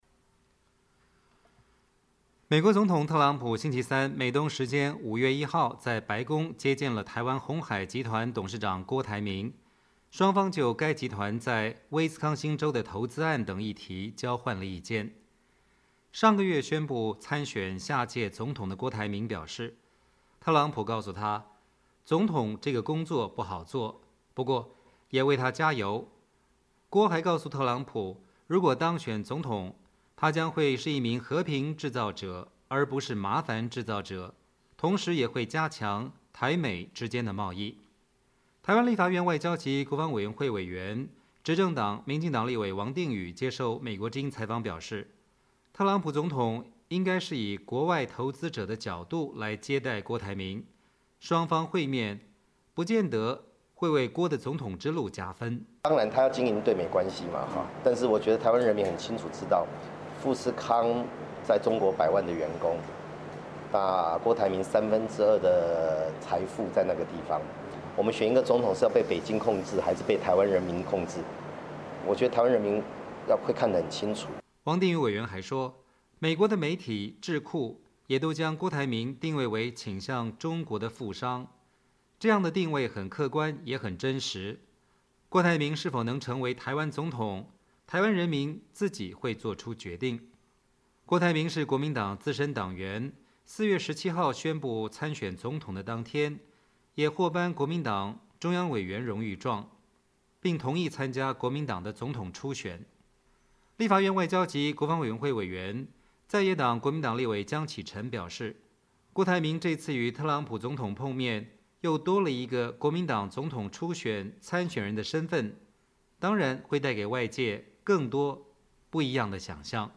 台湾立法院外交及国防委员会委员、执政党民进党立委王定宇接受美国之音采访表示，特朗普总统应该是以国外投资者的角度来接待郭台铭，双方会面不见得为会郭的总统之路加分。